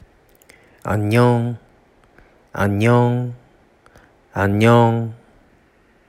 「さようなら（カジュアル）」の発音